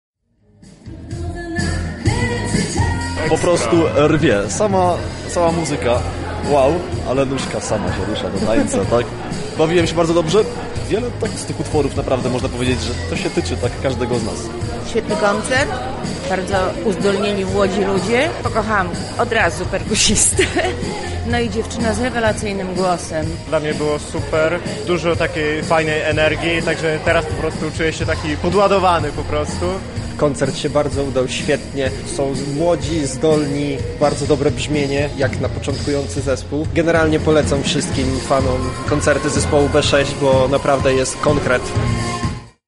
Nasz reporter dowiedział się co najbardziej podobało się przybyłej publiczności. b6rela Koncert wspierany był przez Edukację Kulturalną- Studium Kultury.